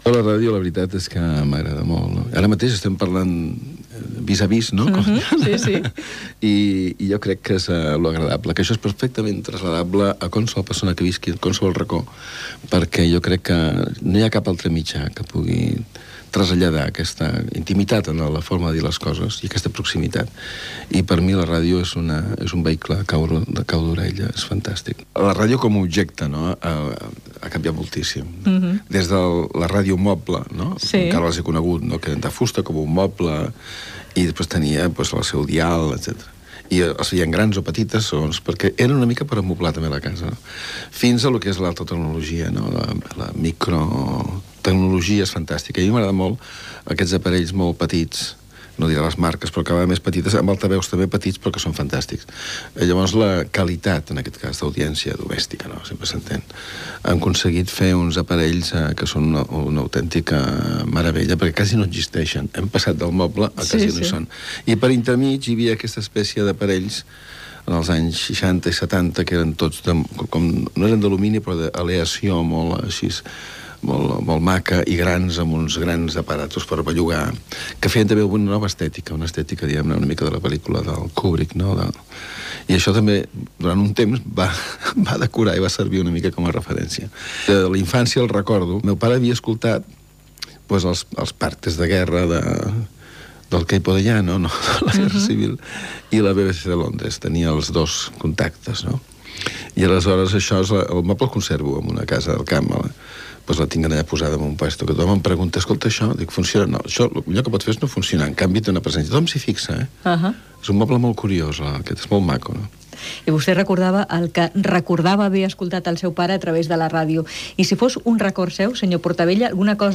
Entrevista sobre la ràdio al director de cinema Pere Portabella